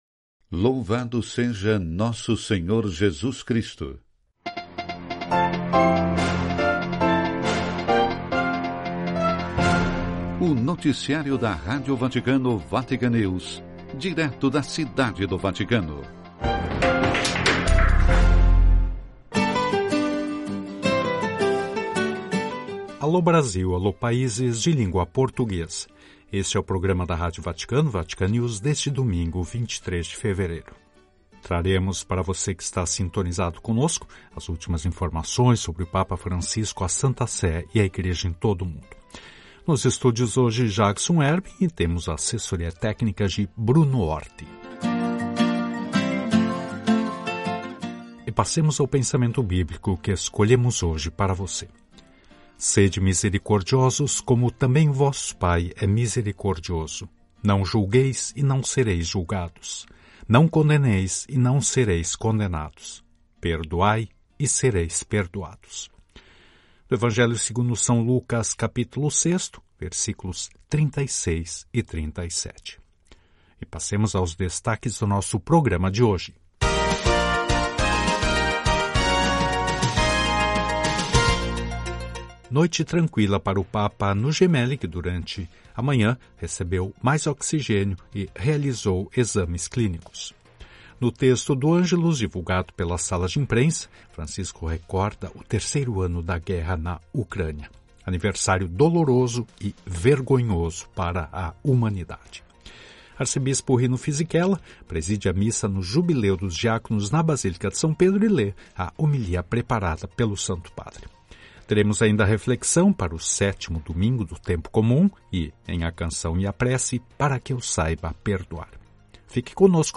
Noticiário (12:00 CET).